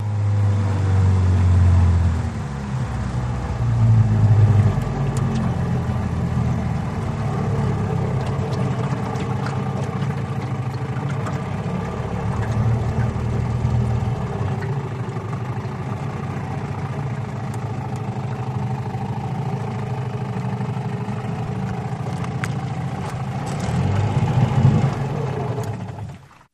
50 hp Evinrude Boat Pull Up Shut Off, On Board